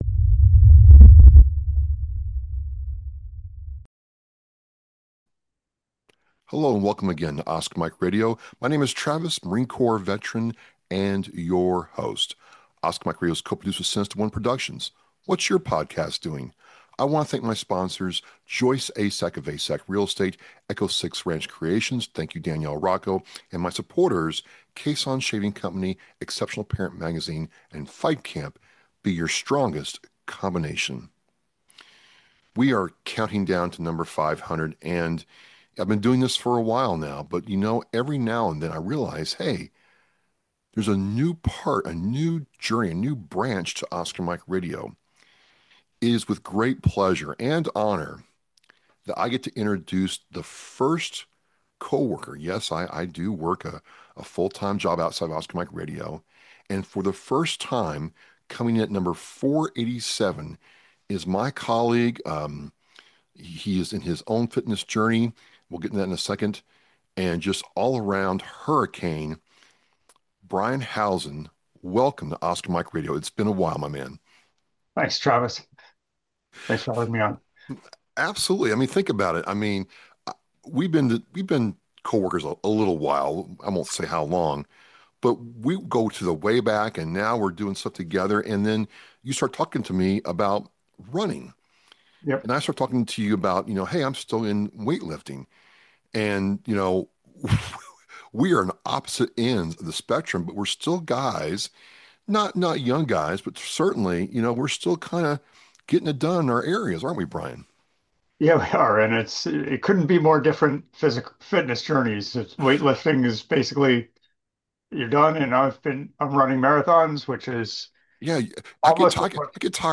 I speak with Marathon runner